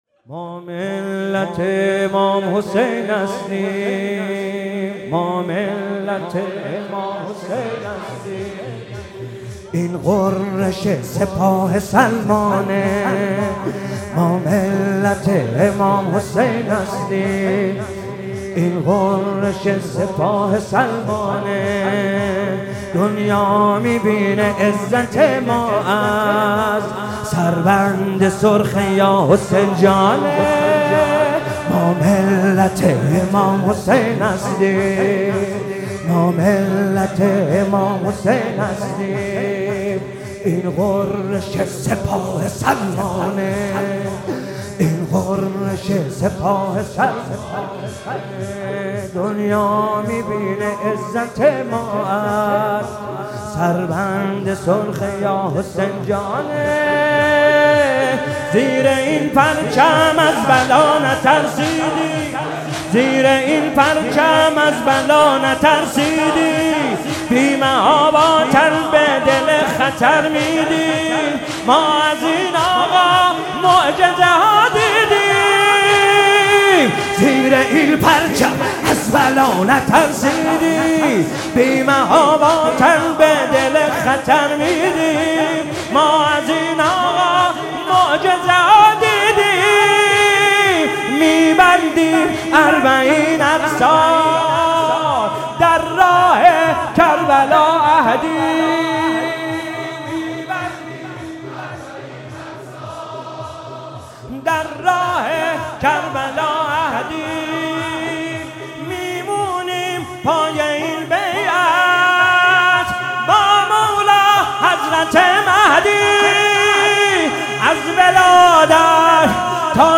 ما ملت امام حسین هستیم مداحی جدید حسین طاهری شب دوم محرم 1400
شب دوم محرم 1400
رجز